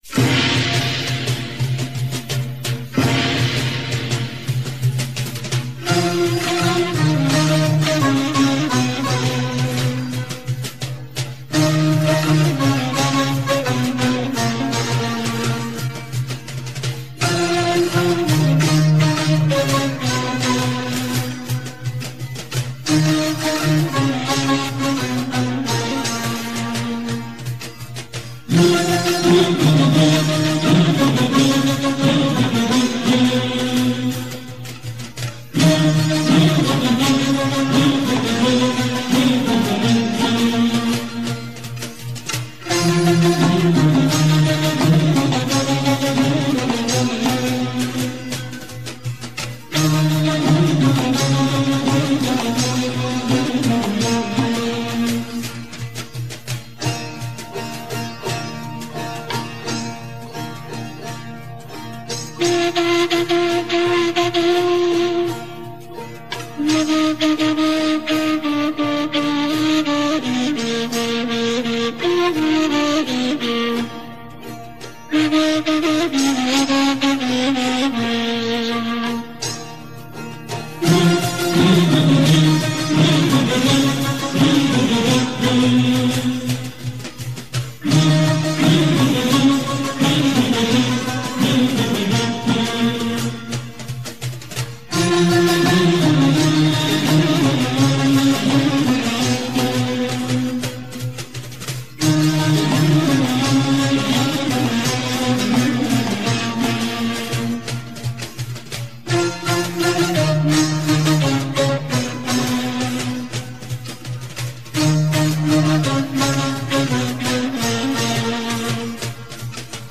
موسيقي بي کلام